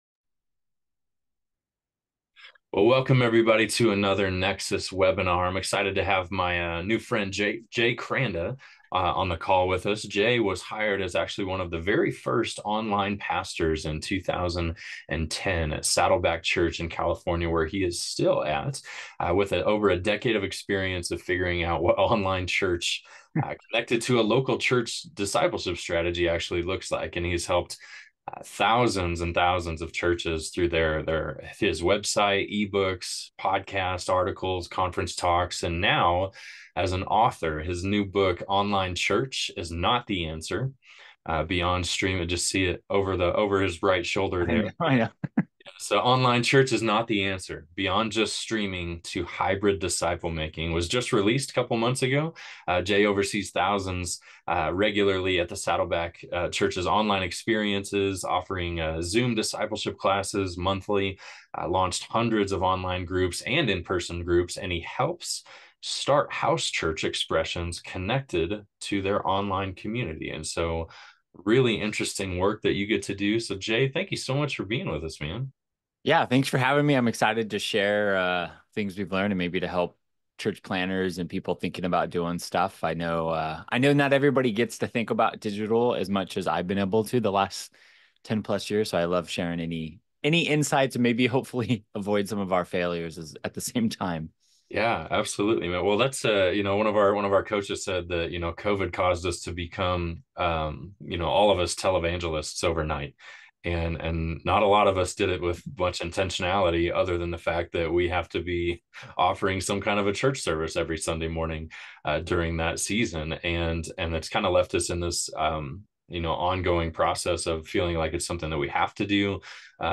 In this webinar, we’ll explore how to build a digital strategy that aligns with your mission and extends beyond your church walls.